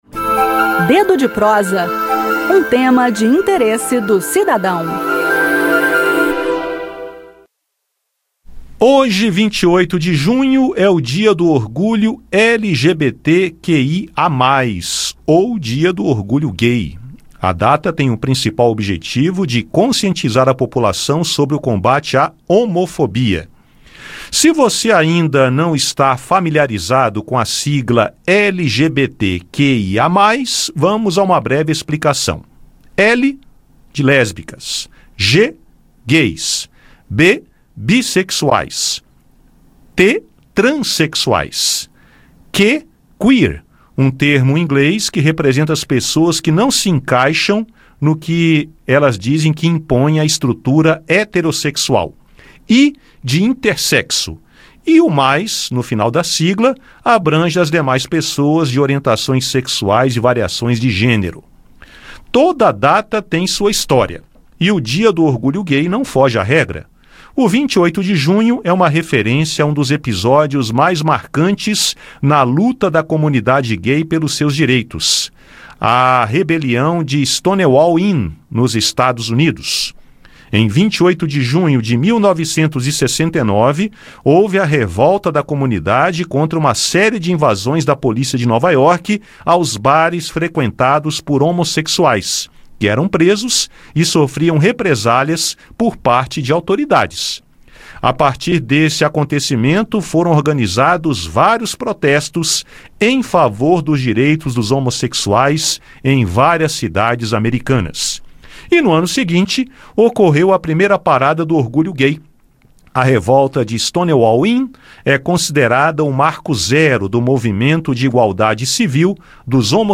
Acompanhe o bate-papo e conheça parte da história da luta do movimento por igualdade civil e os projetos recentes relacionados à defesa e promoção dos direitos de pessoas gays, lésbicas, bissexuais, transexuais e travestis.